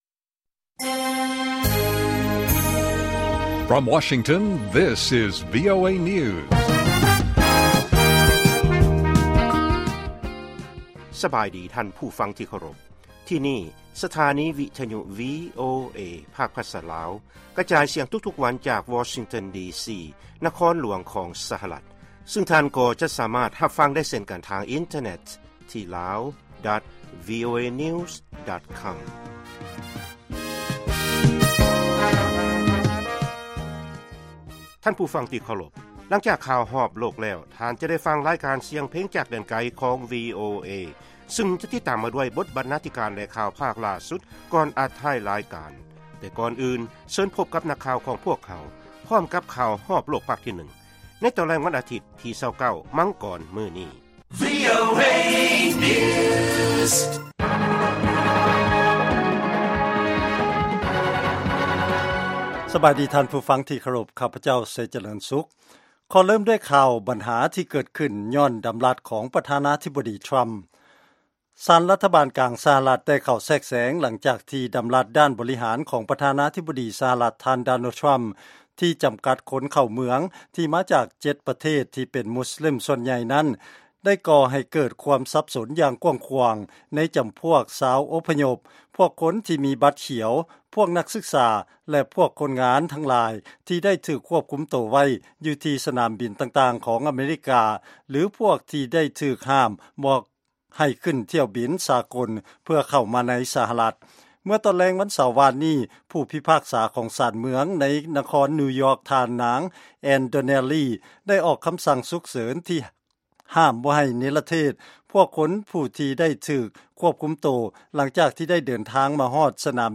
ລາຍການກະຈາຍສຽງຂອງວີໂອເອ ລາວ